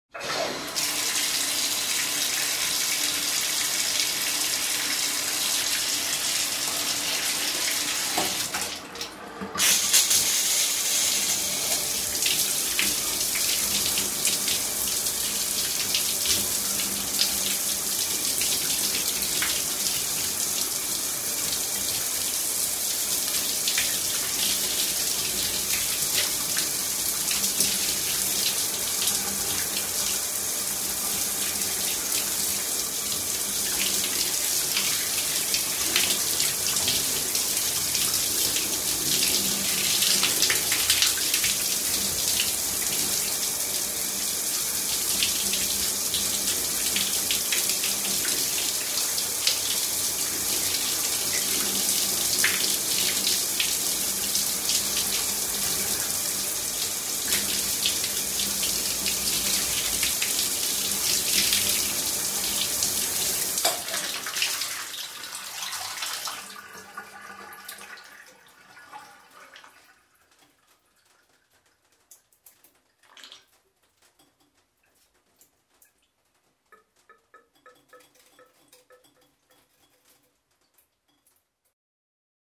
На этой странице собраны разнообразные звуки душа: от мягкого потока воды до интенсивного массажного режима.
Шелест воды в душе